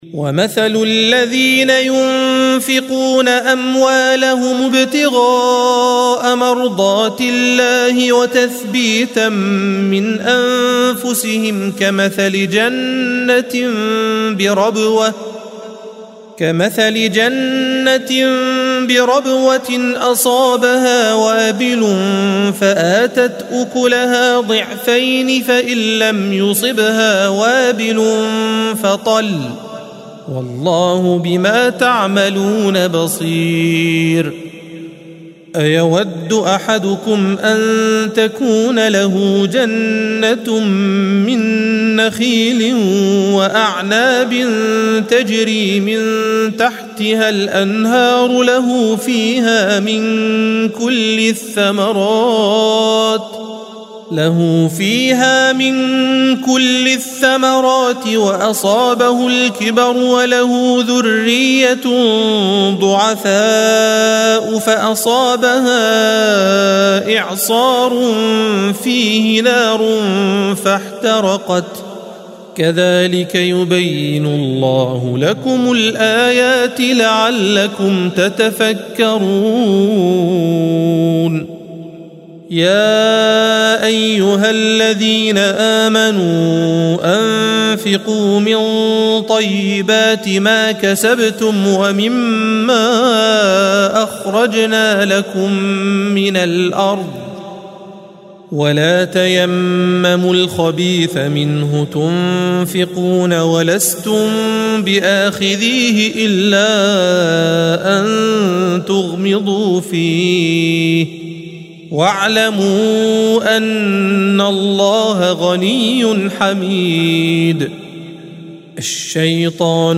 الصفحة 45 - القارئ